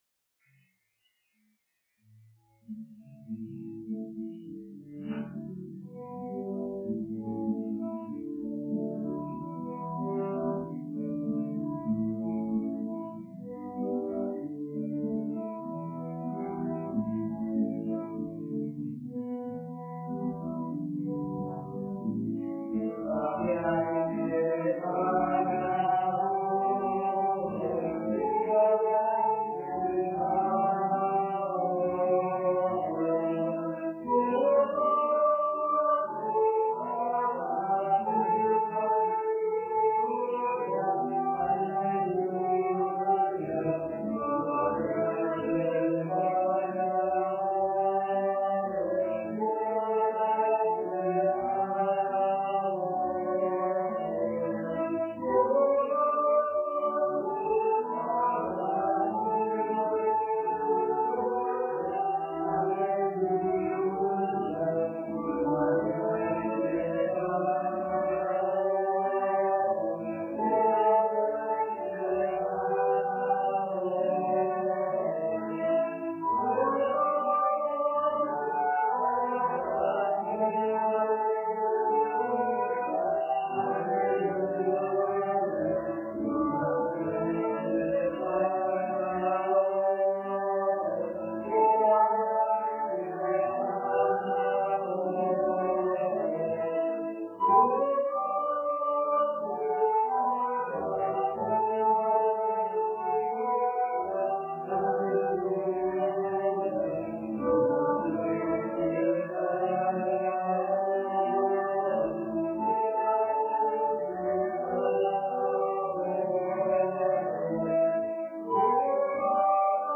Pregària de Taizé
Ermita de Sant Simó - Diumenge 27 de gener de 2013
Vàrem cantar...